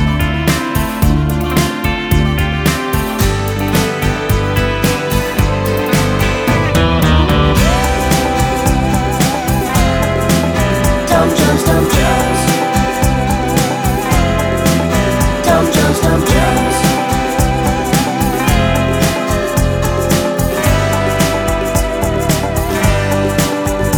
no Backing Vocals Duets 4:08 Buy £1.50